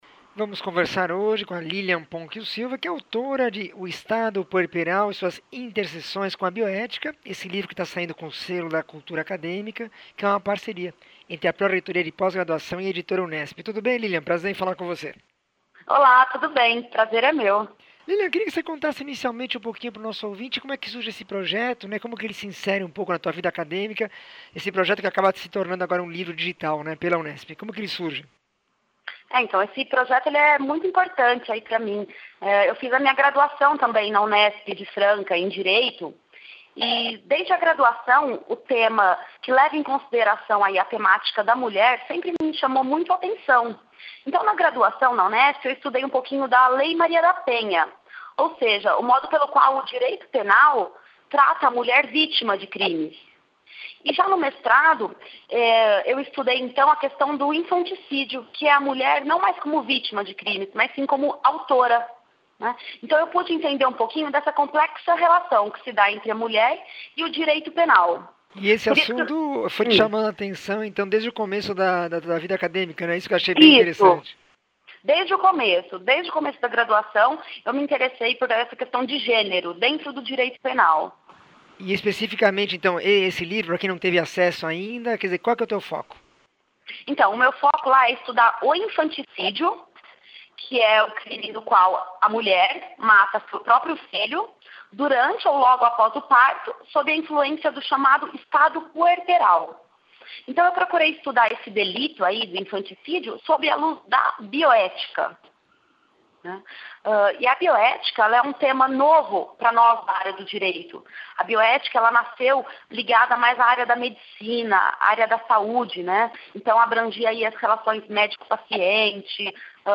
entrevista 1427
Entrevista